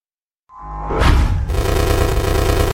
glitch sound
glitch-sound.mp3